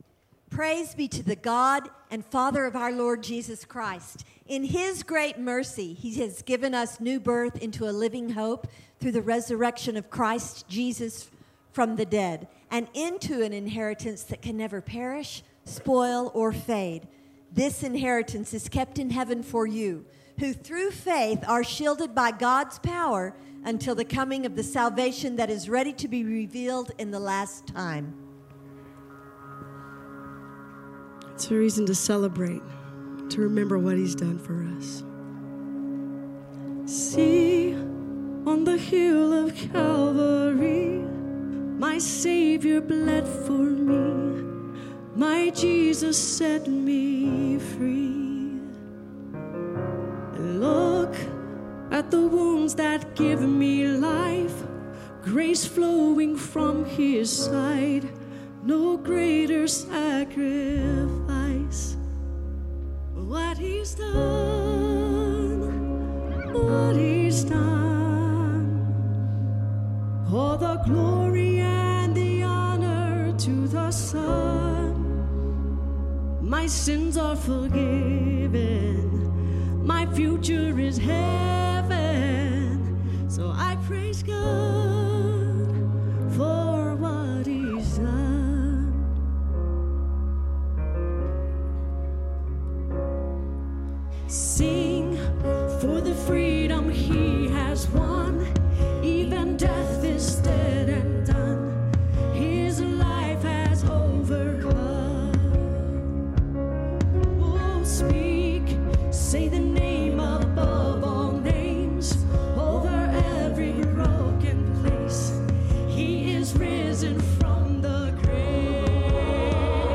Do You Believe in Easter? | Easter Sunday 2023